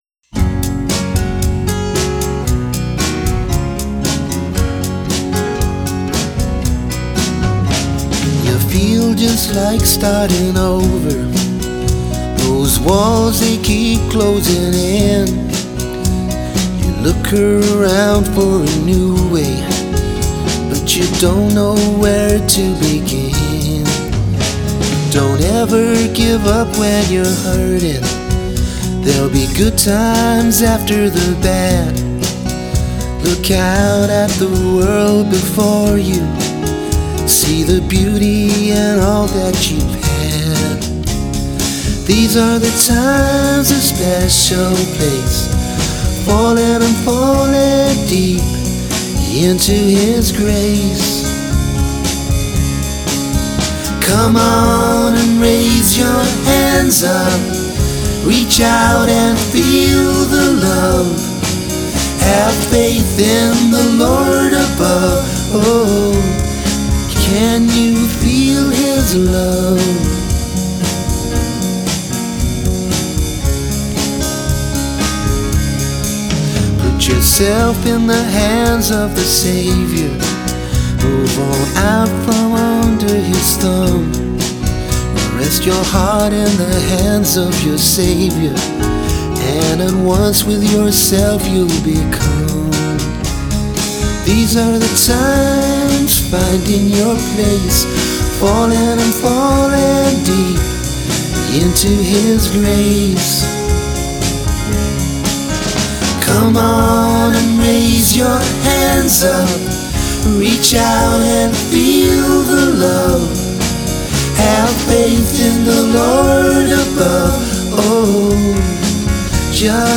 I felt the snare drum could of been a little better.
• The whole song was laid back and relaxing.